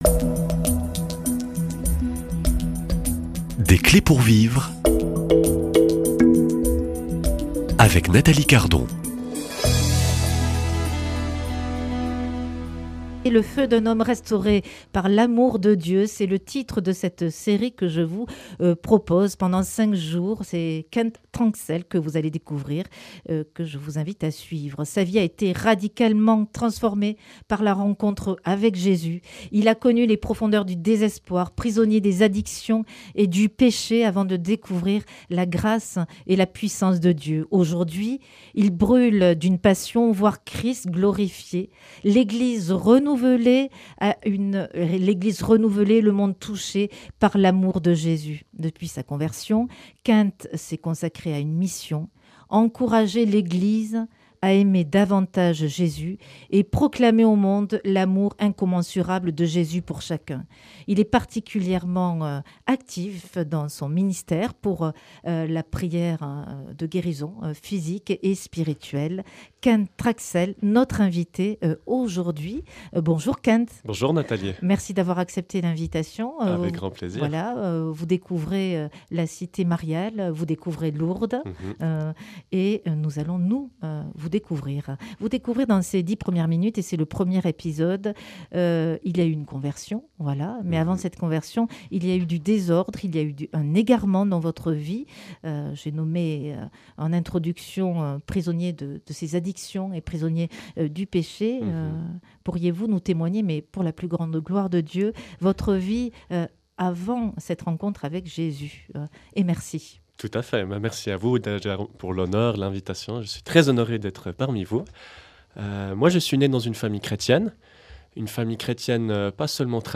Dans cet entretien, il revient avec sincérité sur ce parcours chaotique et sur le changement radical qu’il a vécu à travers sa rencontre avec Jésus. Un témoignage poignant, porteur d’espérance, pour tous ceux qui cherchent une lumière au cœur de la nuit